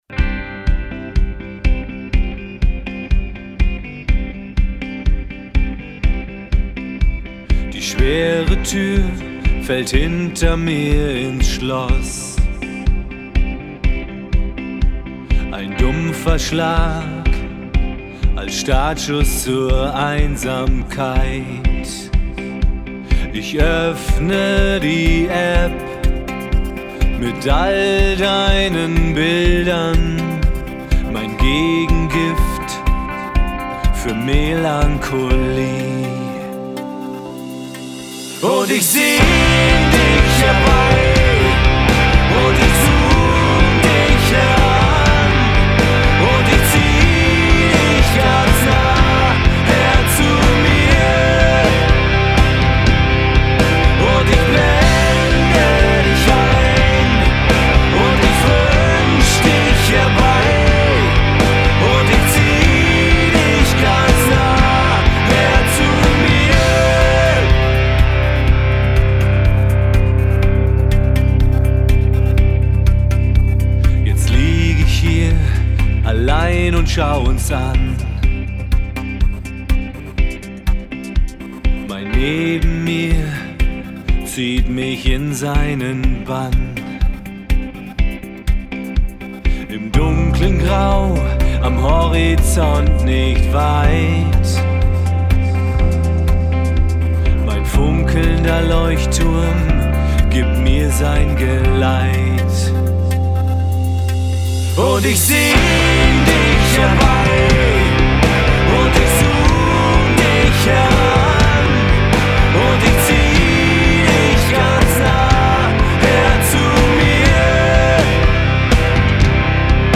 um straighte und melodische Rockmucke zu spielen.